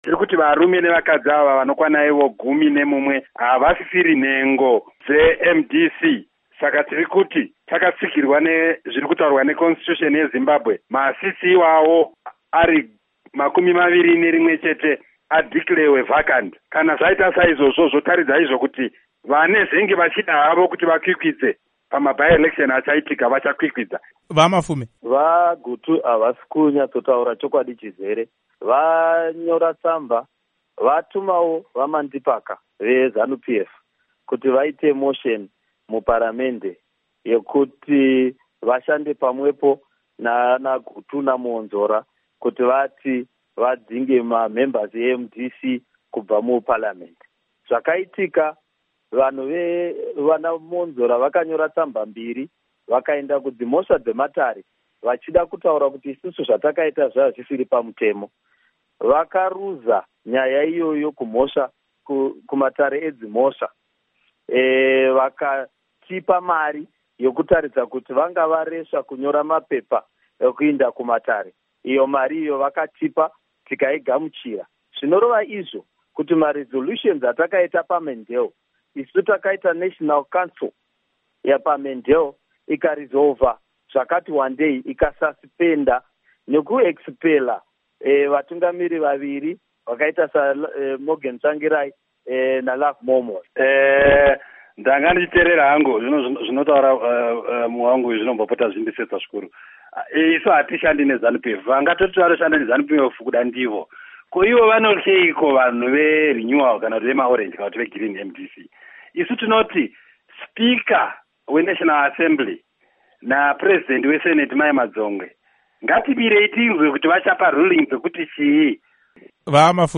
Hurukuro naVaObert Gutu naVaJacob Mafume